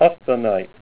Say HOTSONITE-VII